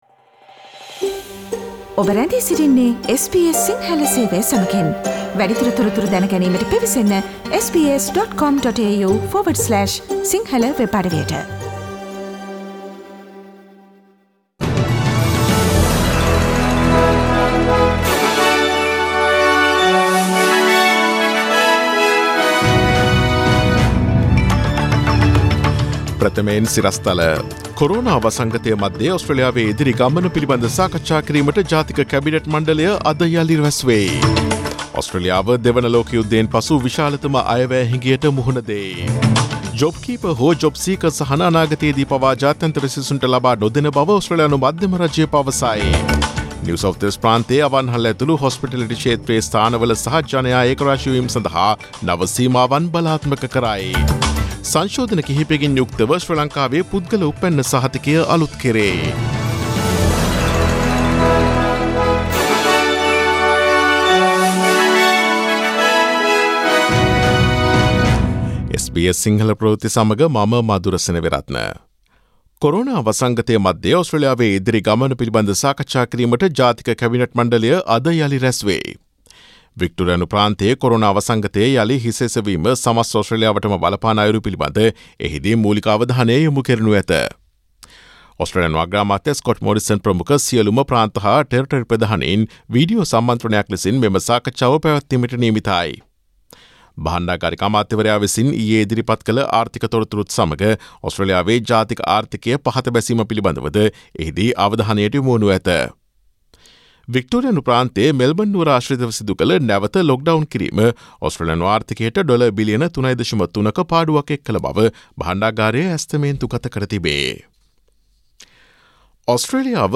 Daily News bulletin of SBS Sinhala Service: Friday 24 July 2020
Today’s news bulletin of SBS Sinhala Radio – Friday 24 July 2020 Listen to SBS Sinhala Radio on Monday, Tuesday, Thursday and Friday between 11 am to 12 noon